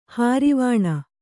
♪ hārivāṇa